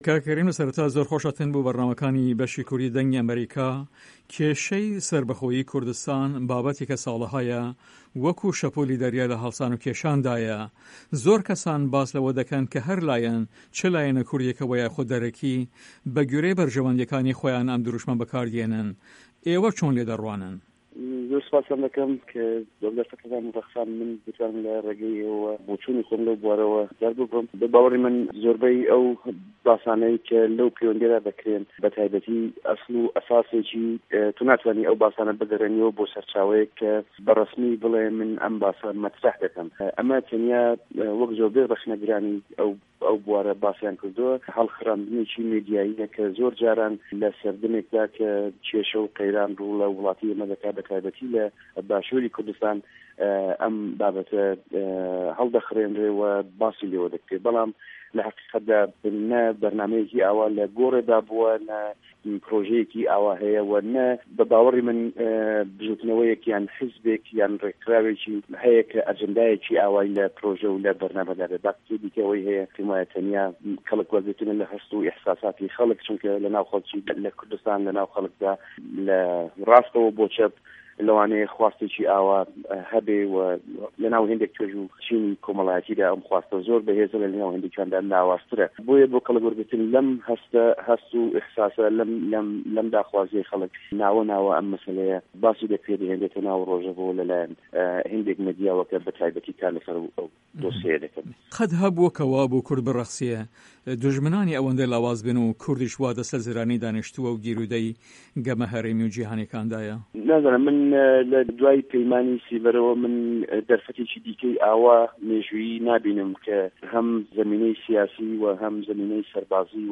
هه‌ڤپه‌یڤنێکدا